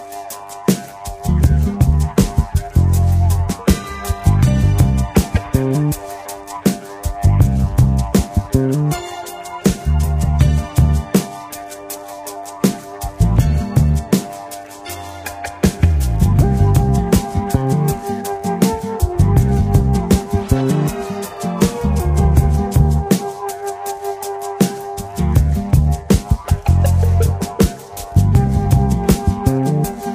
didgeridoo